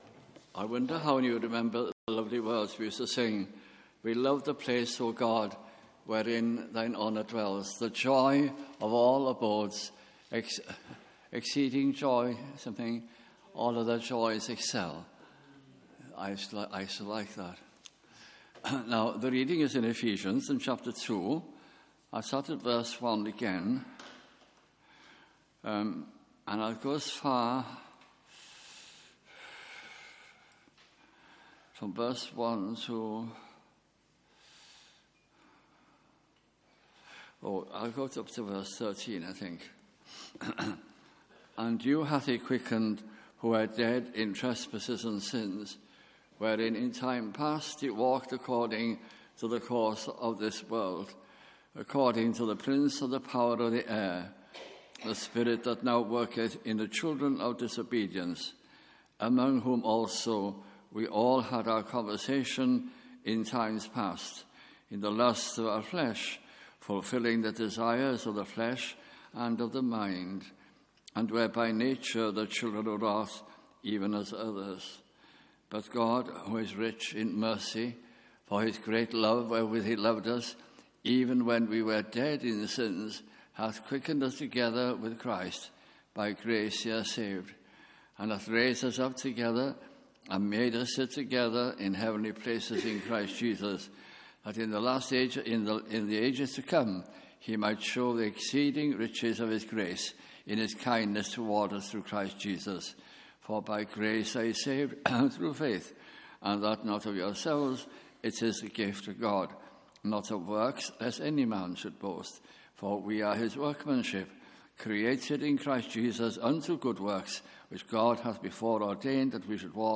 » Ephesians » Bible Study Series 2012 - 2013 » at Tabernacle Cardiff